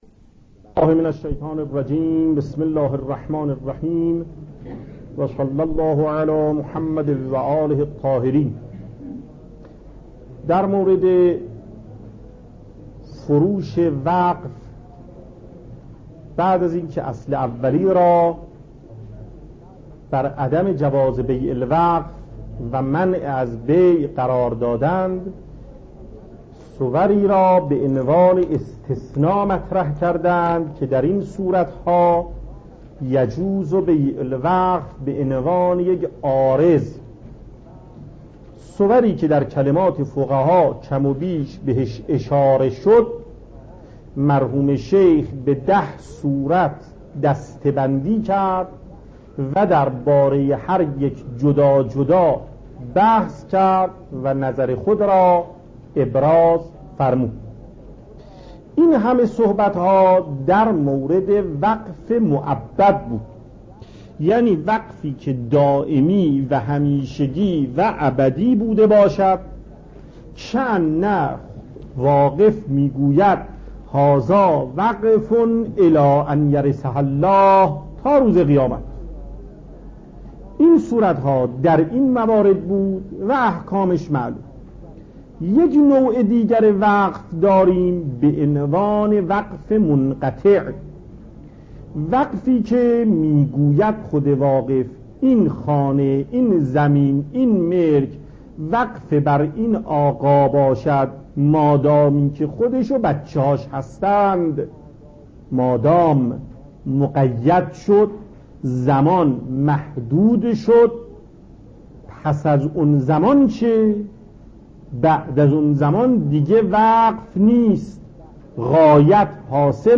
صوت درس
درس مکاسب